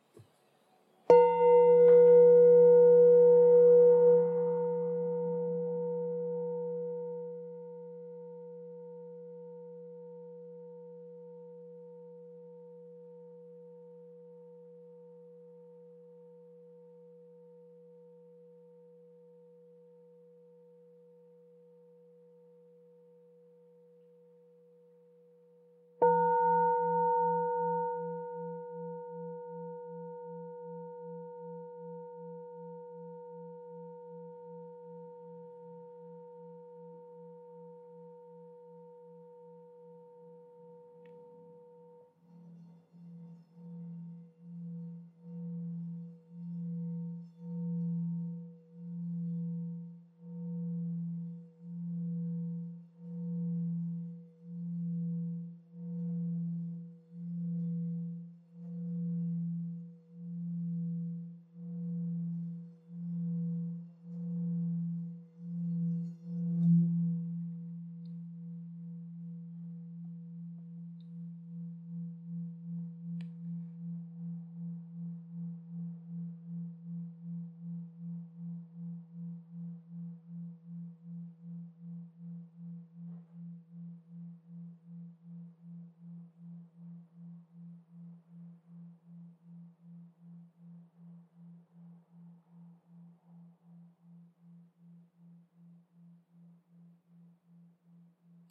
Campana Tibetana THADO Nota FA(F) 3 171 HZ -R020
Nota Armonica    SI(B) 4 487 HZ
Nota di fondo       FA(F) 3 171